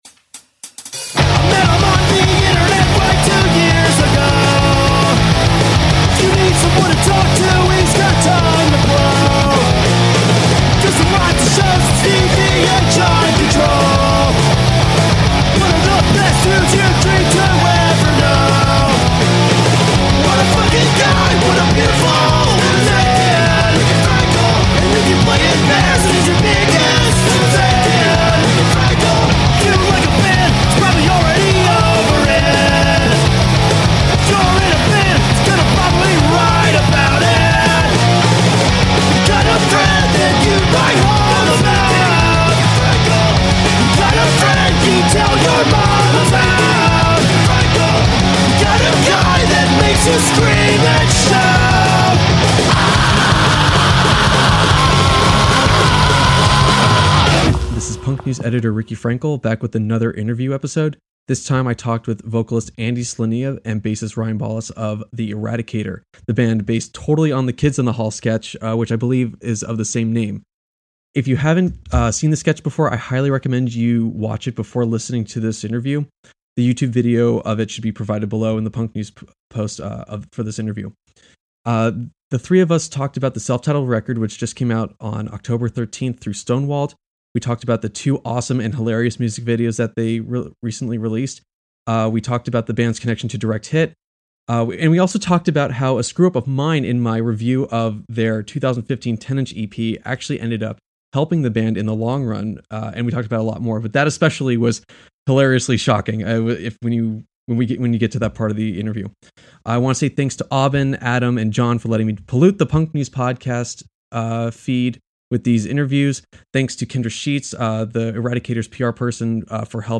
Bonus - Interview with The Eradicator